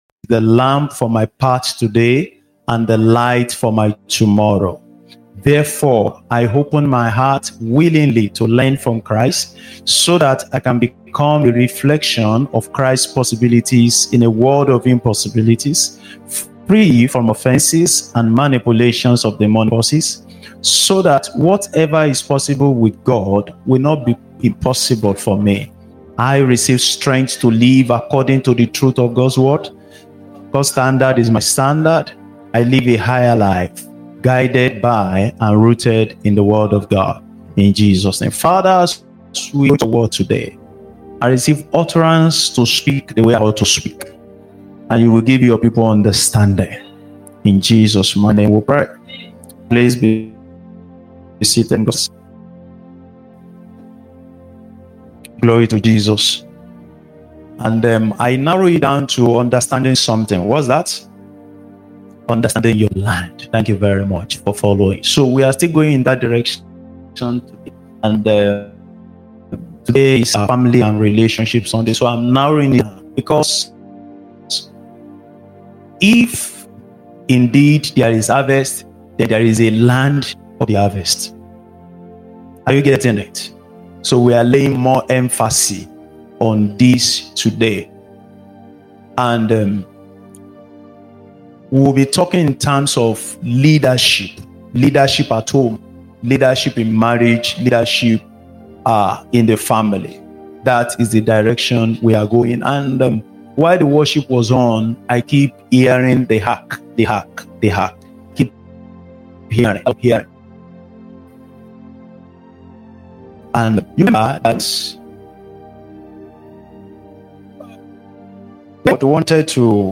Listen to This Powerful Sunday Sermon.